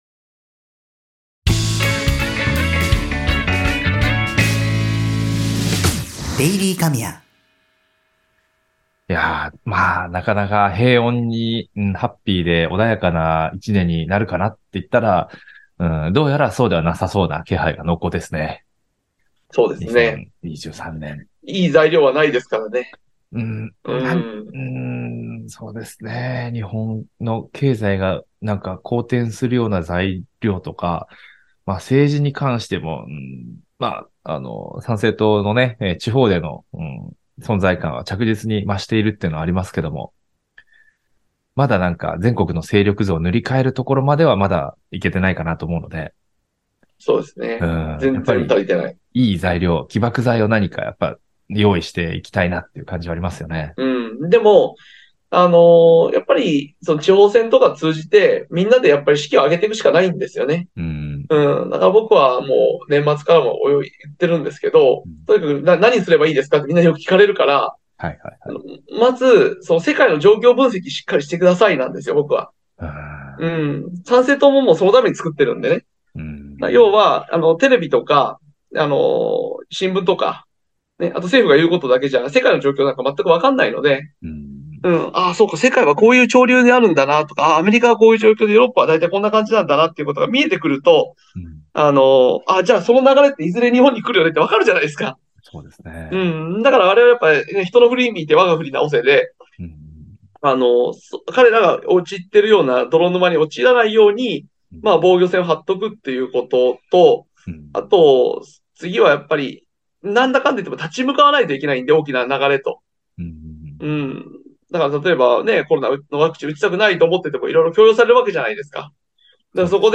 ※各回10分前後の対談音声をお届けします。